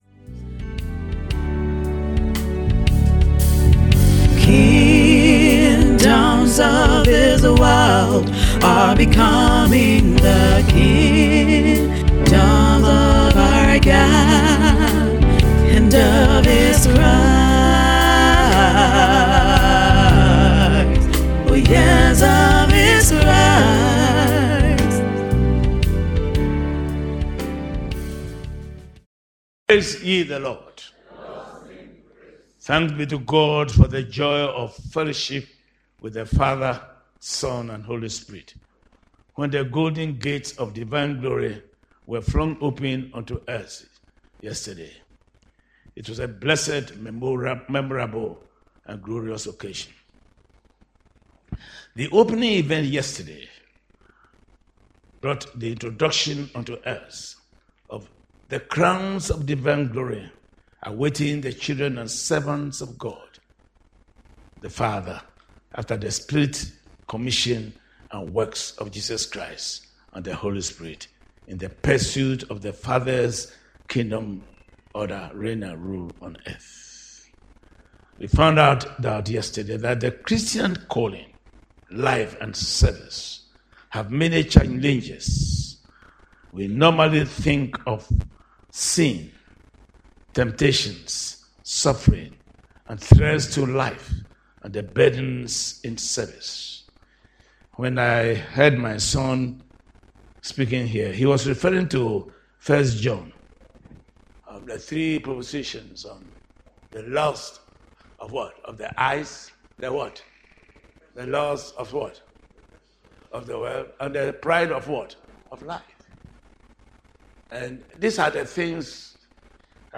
SERMON TITLE: The golden gate highway